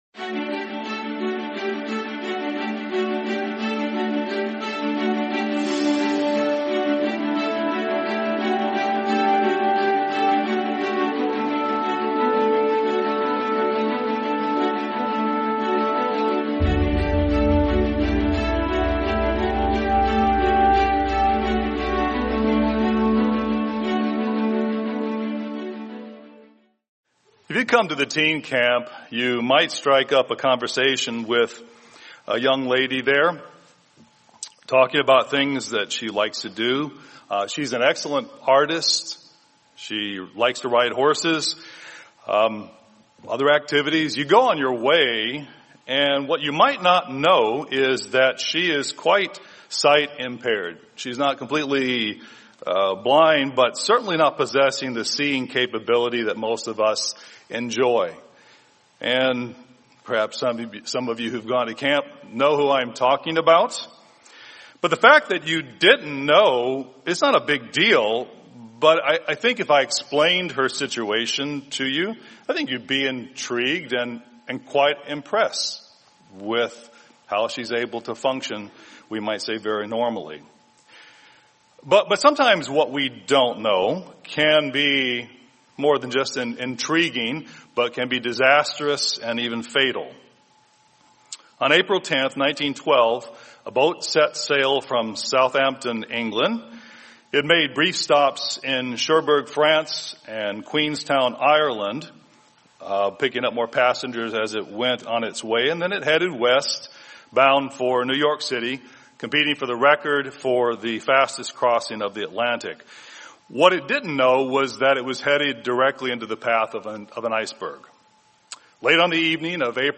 Sermon What We Don't Know